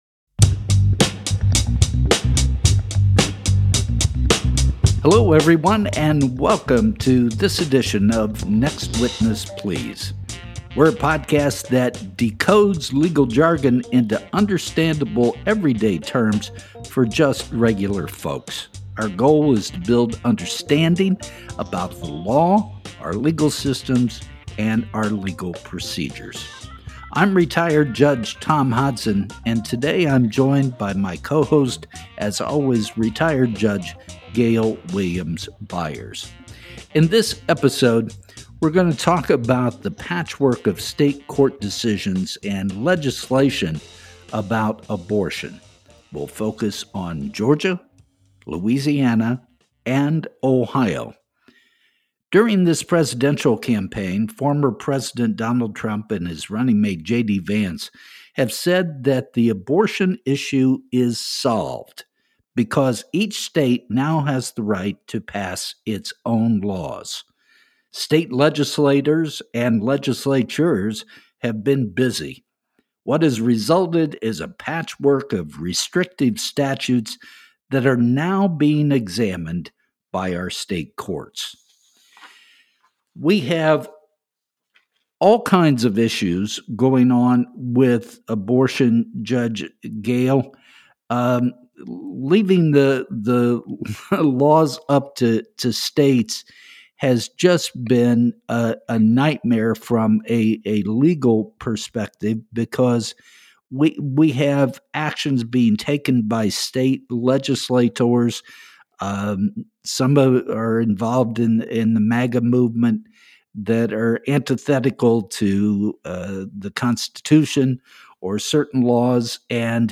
In this episode of “Next Witness…Please,” retired judges Thomas Hodson and Gayle William-Byers focus on three states as examples of abortion chaos at the state level: Georgia, Louisiana and Ohio.